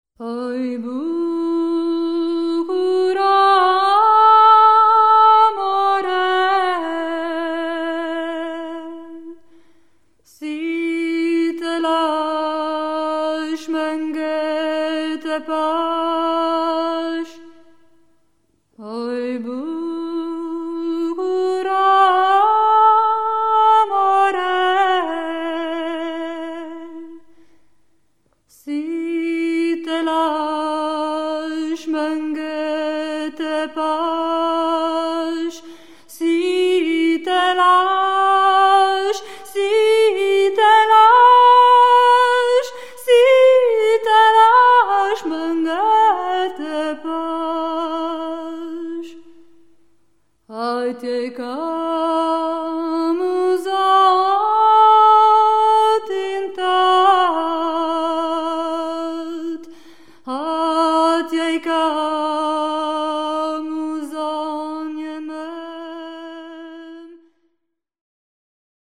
Volkslieder aus Süditalien
Gesang, Perkussion
Gitarre